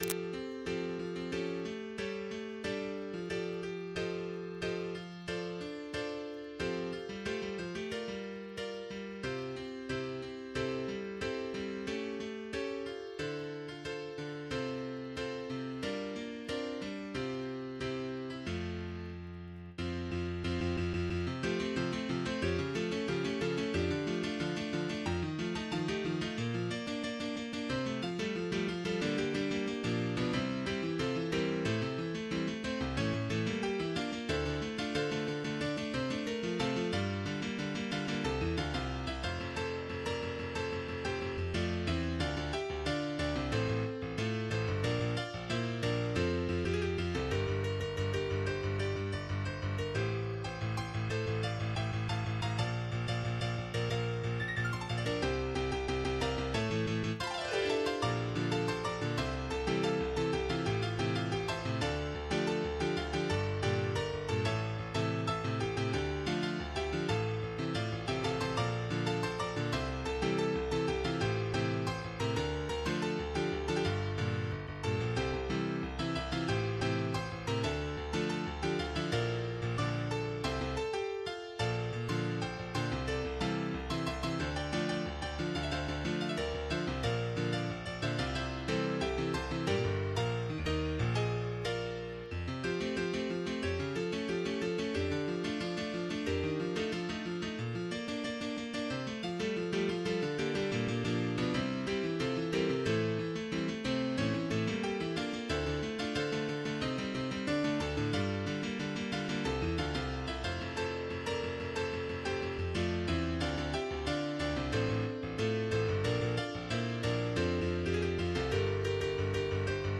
MIDI 29.44 KB MP3
Piano cover